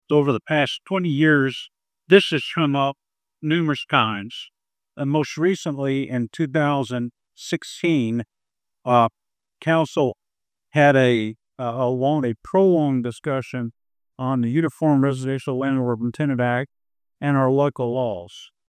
The Human Rights Commission of Hopkinsville–Christian County hosted its second public forum on the Uniform Residential Landlord–Tenant Act.